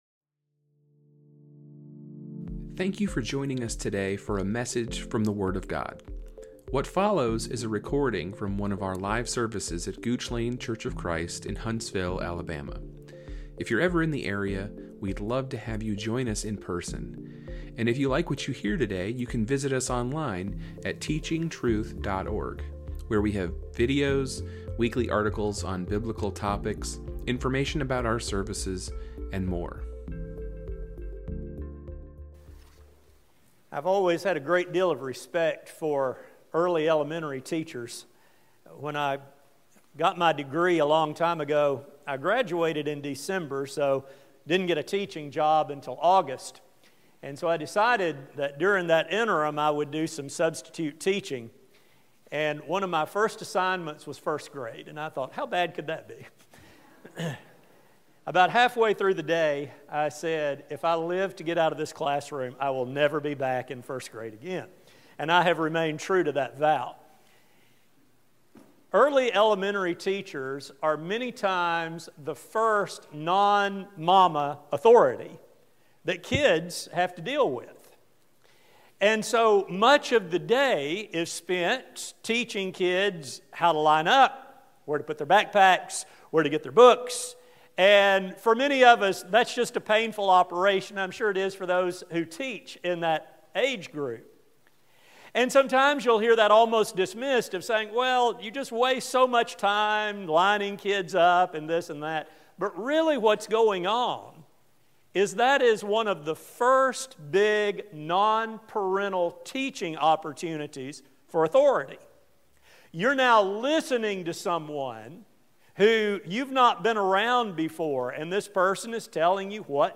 As our Holy Conduct series continues, this study will focus on how we should view God's commands and our attitude toward His authority. A sermon